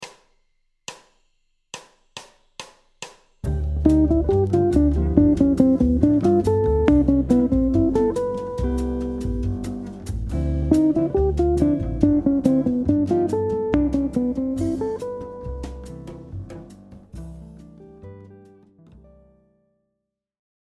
A partir d’une note guide de l’accord, le motif suit une séquence descendante de chromatismes par 1/2 ton successifs.
Chromatismes descendants de la Quinte de G7 (note D) vers la Tierce Majeure (note B)
Phrase-05-Cadence-ii-V7-I.mp3